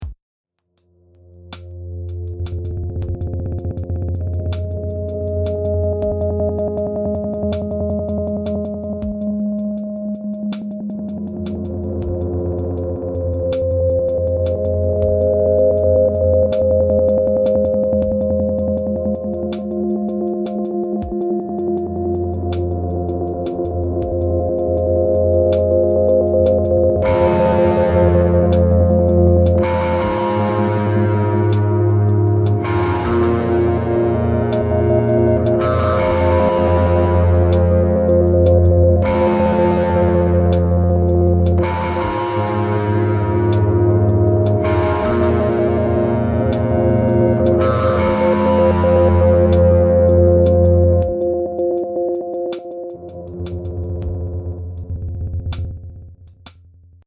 Electrónica oscura